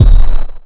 mnl-bdrum2.wav